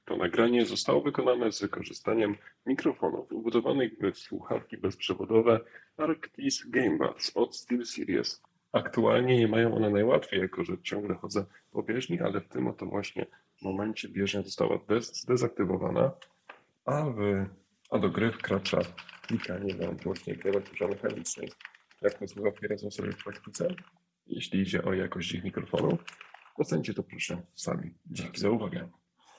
Test-mikrofon-Arctos-GaeNids.mp3